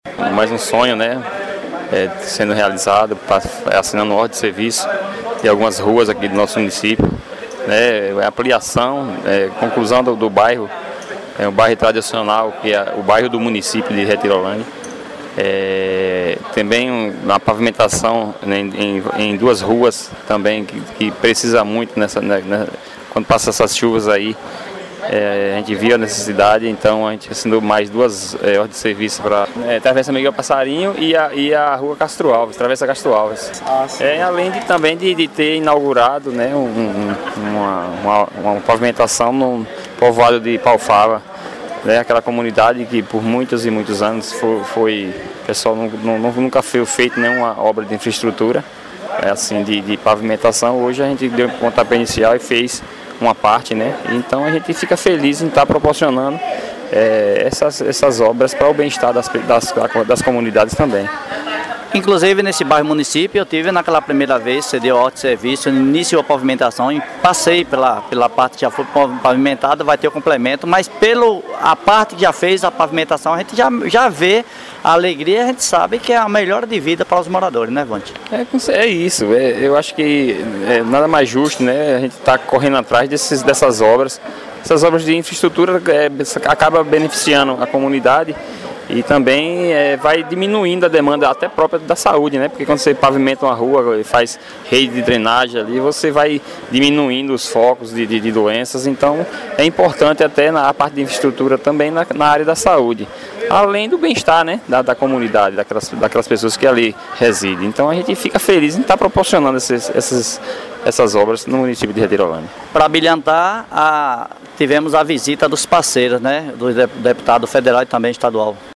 O prefeito Vonte destacou principalmente a capacidade que sua gestão tem em fazer com recursos próprios algumas obras.